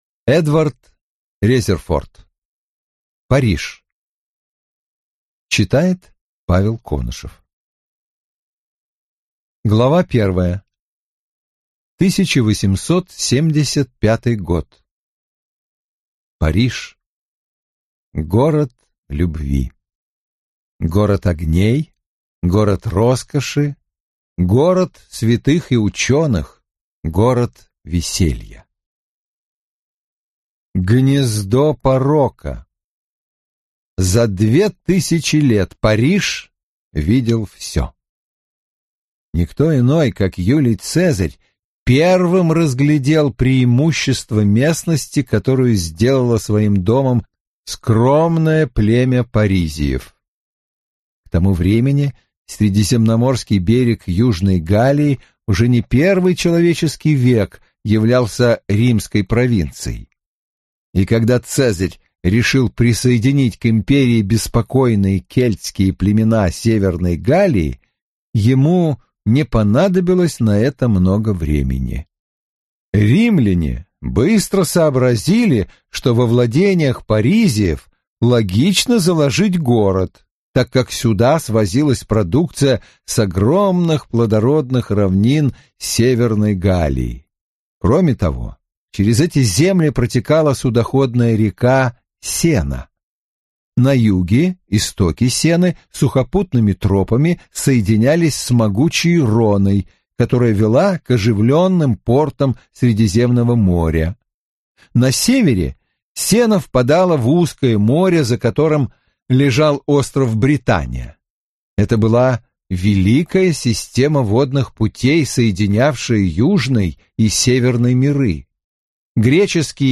Аудиокнига Париж. Том 1 | Библиотека аудиокниг